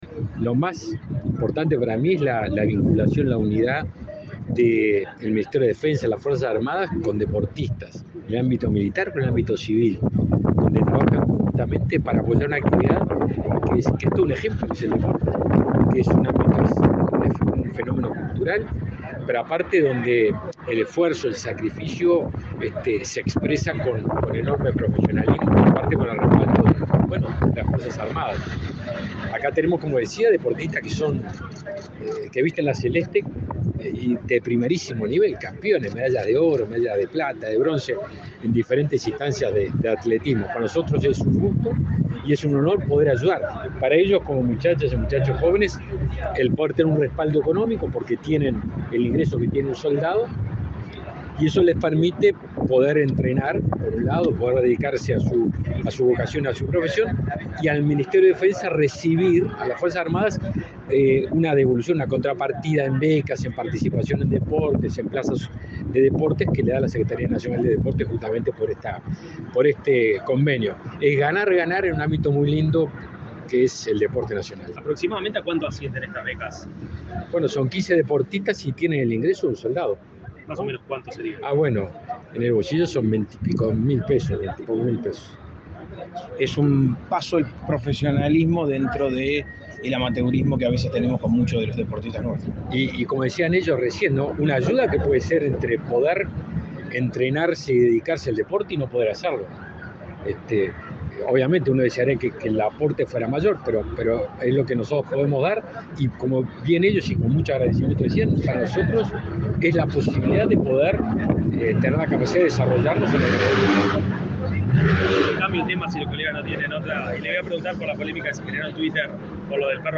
Declaraciones del ministro de Defensa, Javier García
Luego García dialogó con la prensa.